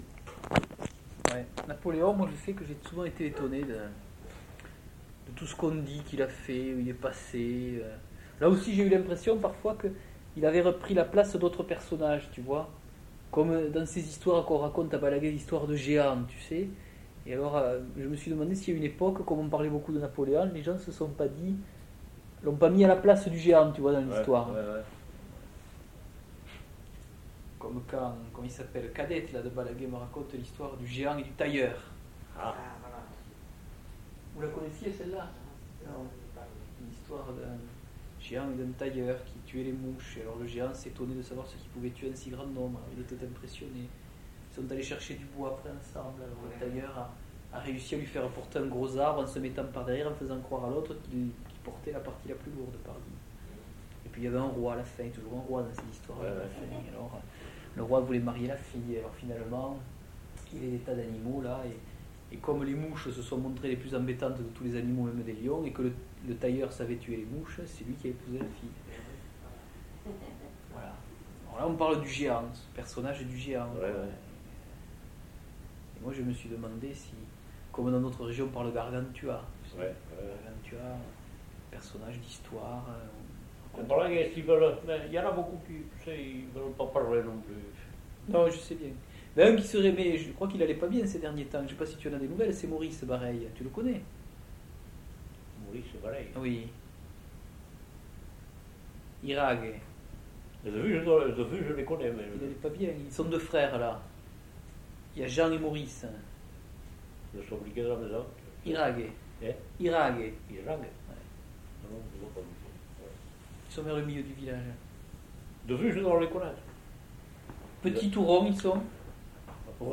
Aire culturelle : Couserans
Lieu : Pouech de Luzenac (lieu-dit)
Genre : témoignage thématique
Notes consultables : En fin de séquence, l'enquêteur raconte l'histoire de Pierròt de Balagué.